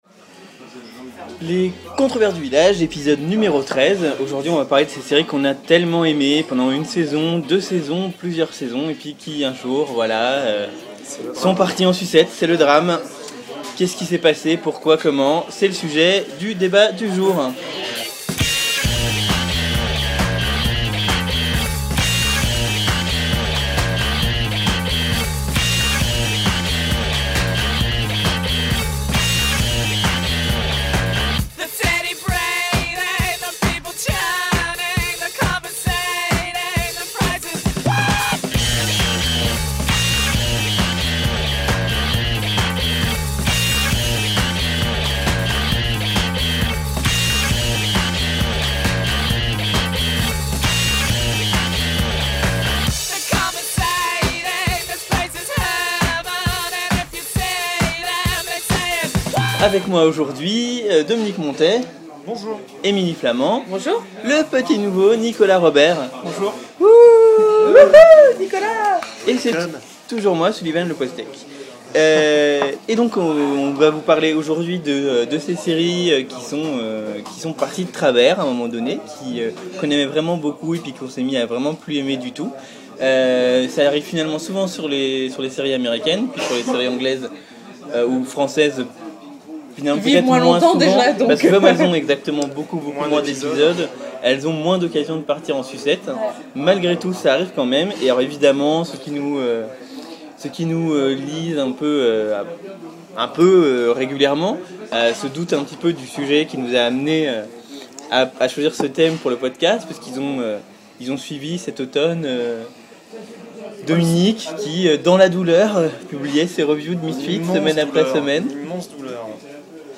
Dans ce treizième numéro des Controverses, nous débattons de certaines de ces séries qui ont pris un virage pour le pire.